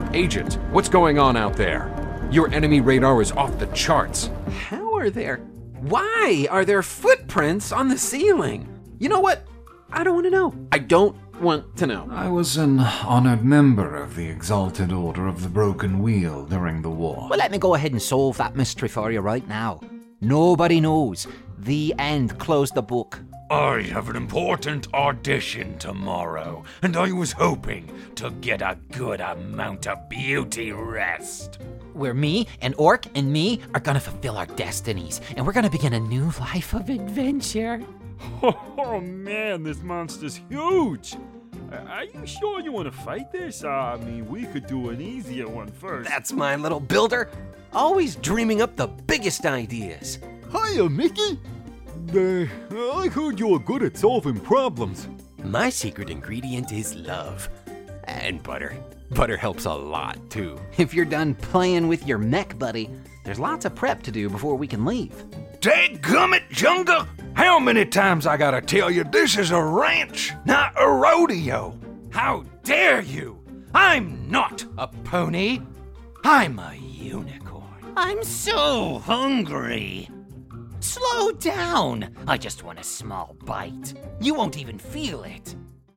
Has Own Studio
ANIMATION 🎬